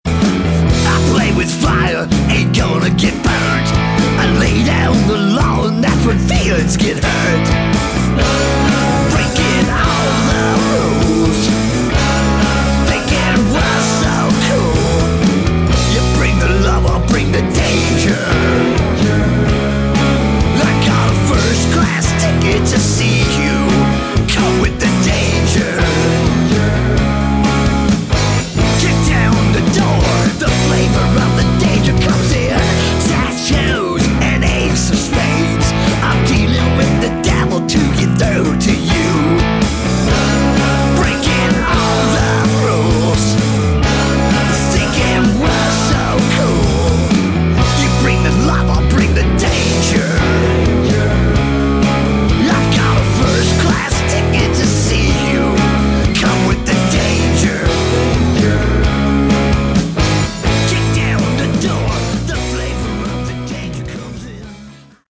Category: Sleaze Glam/Punk
lead vocals, guitars
bass, piano, mandolin, percussion, backing vocals
drums, backing vocals
guitar, ebow, backing vocals
acoustic guitar, electric steel, backing vocals